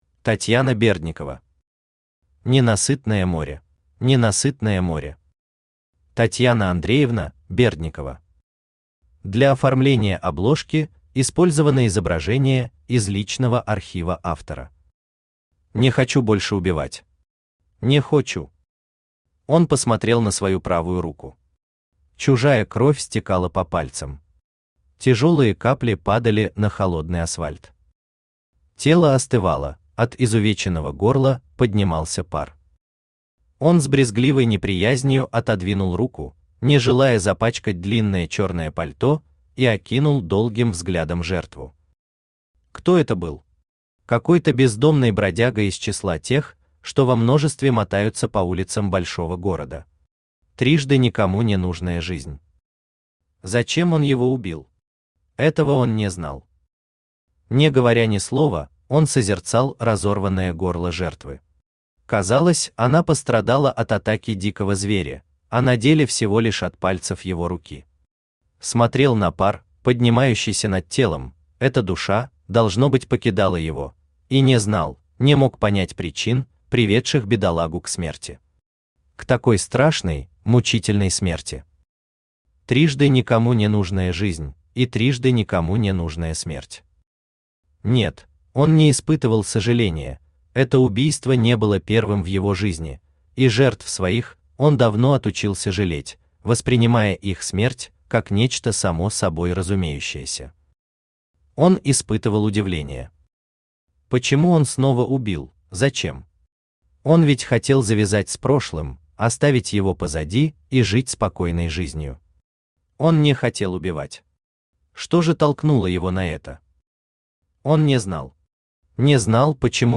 Aудиокнига Ненасытное море Автор Татьяна Андреевна Бердникова Читает аудиокнигу Авточтец ЛитРес.